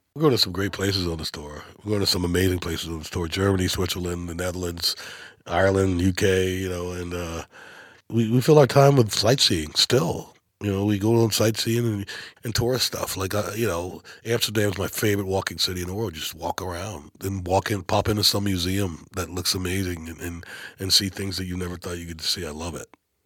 Darius Rucker talks about the amazing places he gets to play and visit on his European Tour.